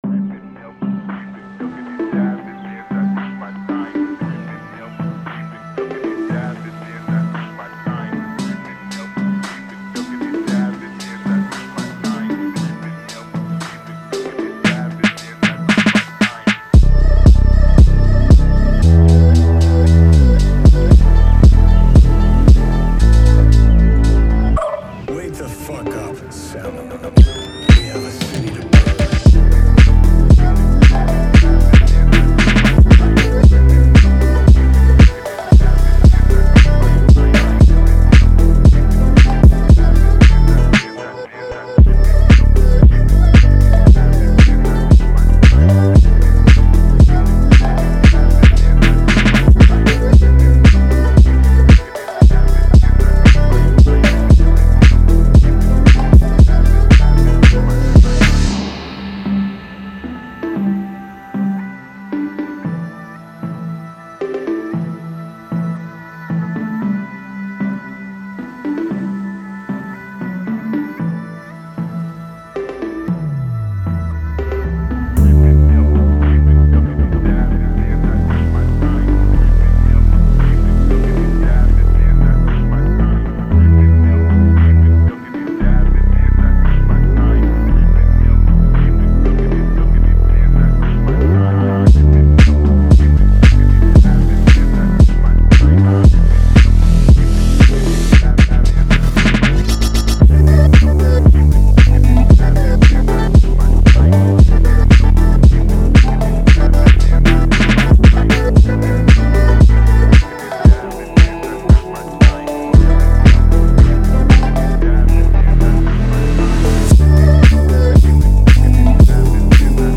Пхонк на зацен (помощь бы)
Идея мне нравится, но, кажется, скучновато звучит, неполноценно.